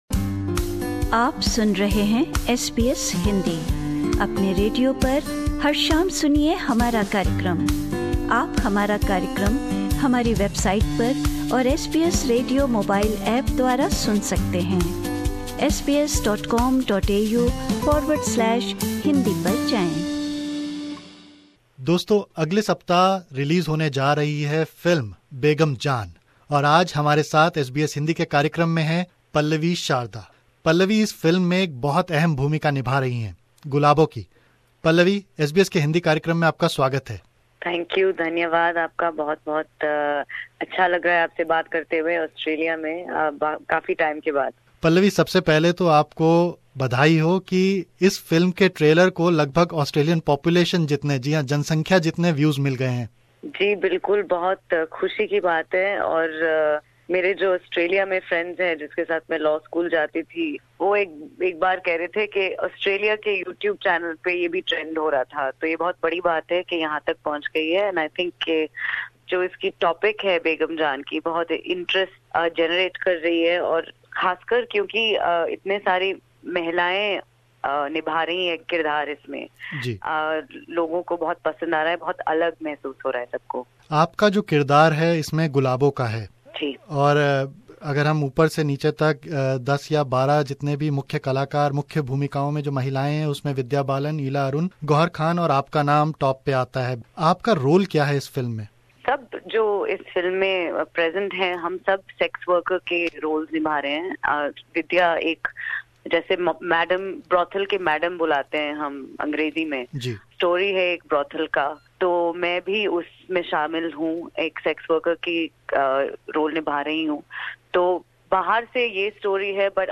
ख़ास बातचीत